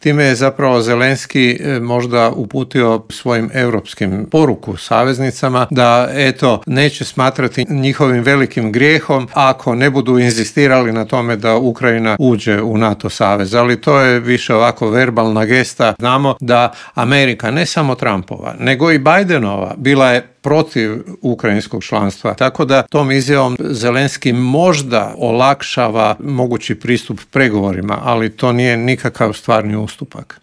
Na ta i ostala povezana pitanja u intervjuu Media servisa odgovorio je vanjskopolitički analitičar i bivši ambasador Hrvatske u Moskvi Božo Kovačević.